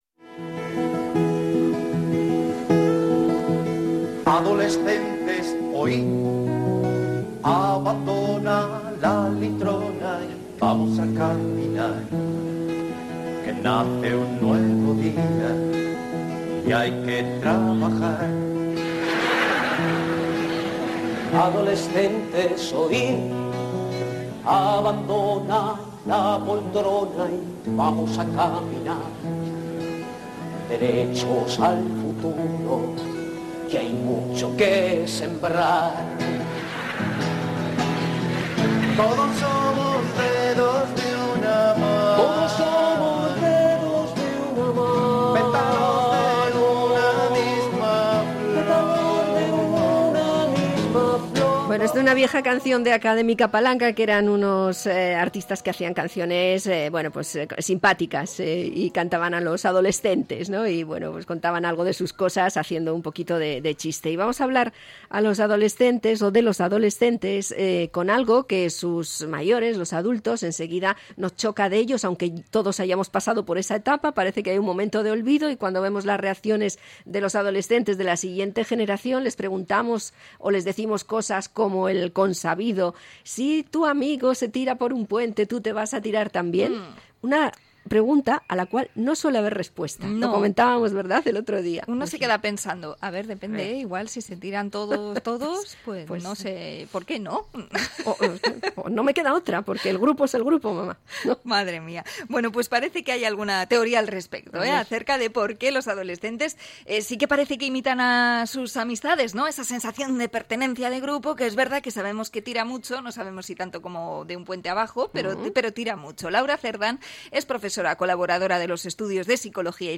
Entrevista a psicóloga de la UOC sobre la pertenencia al grupo en la adolescencia